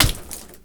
FISH SOUNDS:
fishdrop.wav